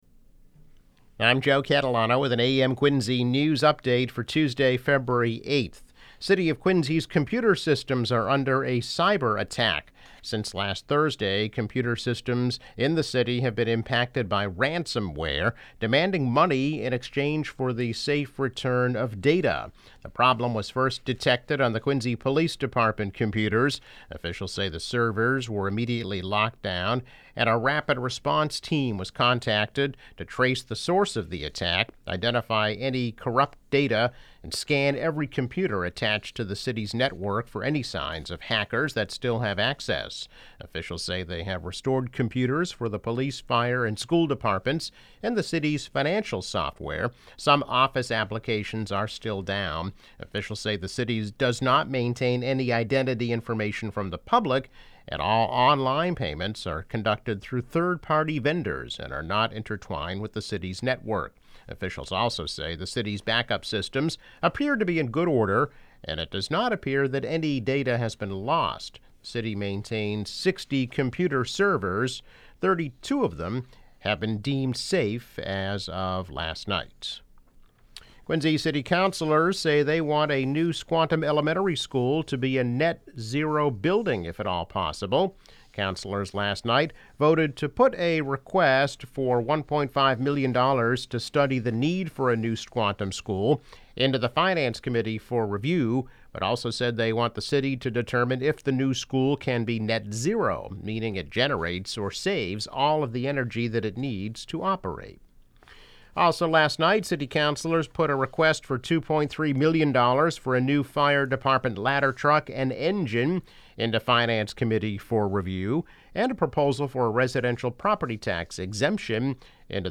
Cyber attack. Net zero school. New fire engines.  Daily news, weather and sports update.